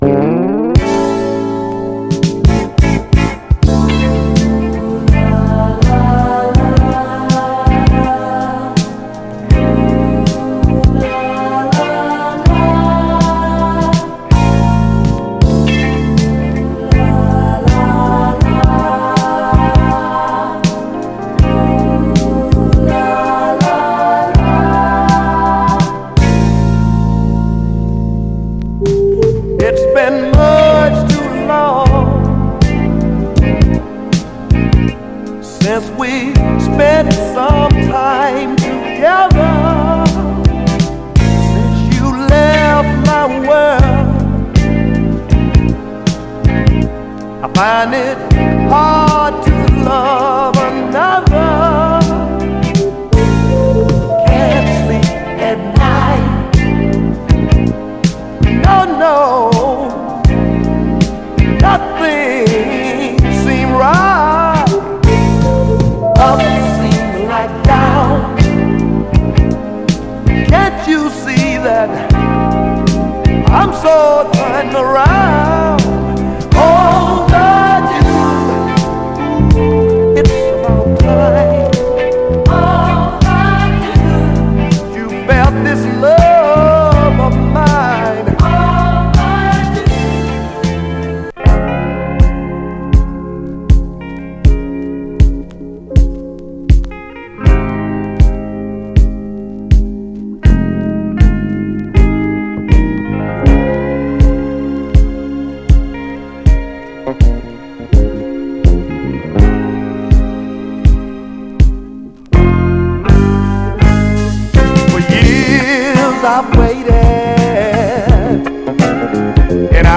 両面素晴らしいモダンソウルのダブル・サイダーです！
※試聴音源は実際にお送りする商品から録音したものです※